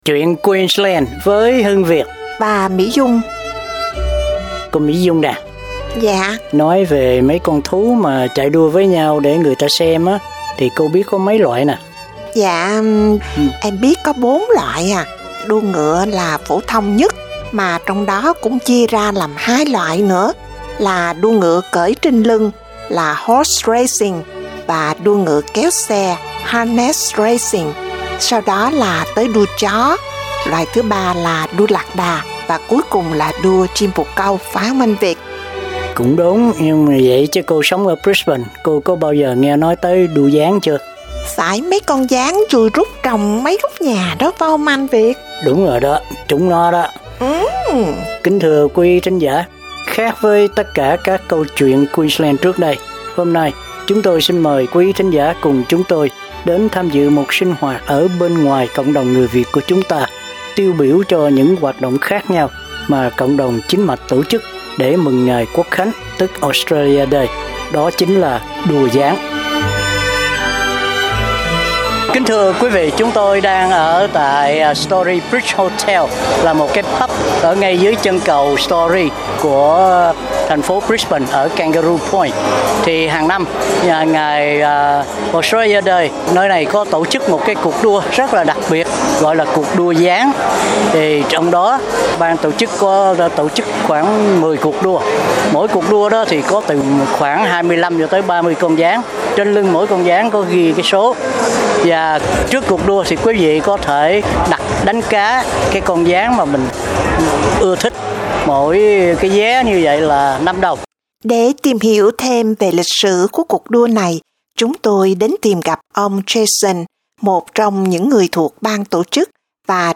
Chúng tôi đang ở tại Story Bridge Hotel là một cái Pub ở ngay dưới chân cầu Story của thành phố Brisbane ở Kangaroo Point, thì hàng năm vào ngày Australia Day, nơi này có tổ chức một cuộc đua rất là đặc biệt gọi là cuộc đua gián thì trong đó ban tổ chức có tổ chức khoảng 10 cuộc đua, mỗi cuộc đua có khoảng 25 cho tới 30 con gián, trên lưng mỗi con gián có ghi cái số.